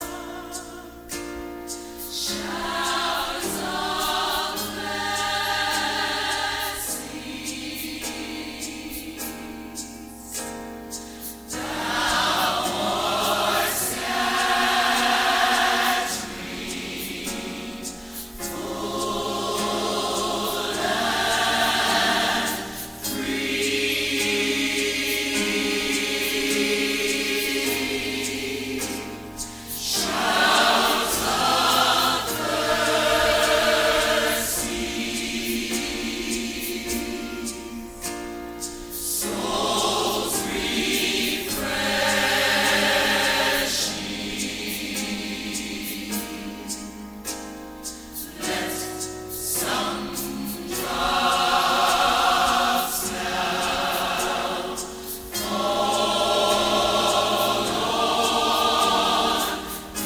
im Stil aktueller Gospelmusik
von herausragenden Sängern und Sängerinnen eingesungen
• Sachgebiet: Praise & Worship